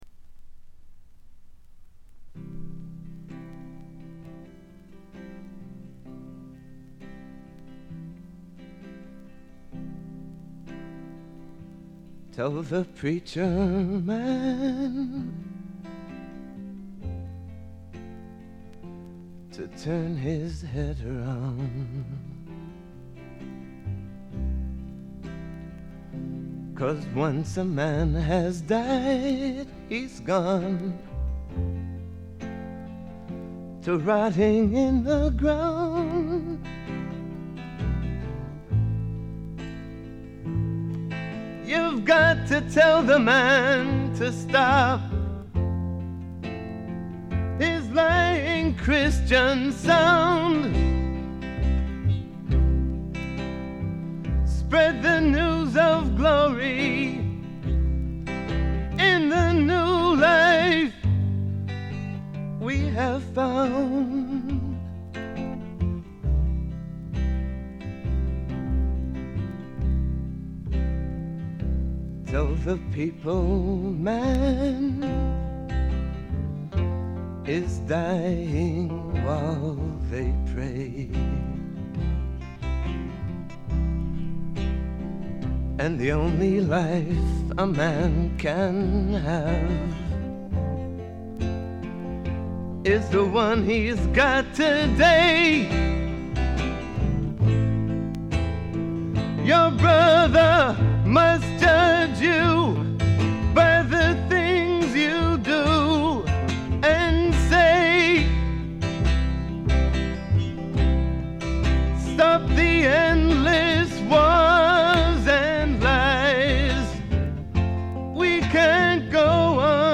ほとんどノイズ感無し。
もともとは楽曲ライター志望だったようで曲の良さはもちろんのこと、ちょっとアシッドなヴォーカルが素晴らしいです。
メランコリックでビター＆スウィートな哀愁の名作。
試聴曲は現品からの取り込み音源です。